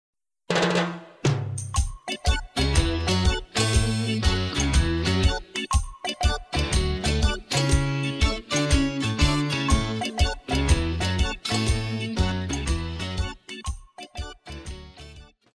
套鼓(架子鼓)
乐团
演奏曲
雷鬼乐,流行音乐
独奏与伴奏
有主奏
有节拍器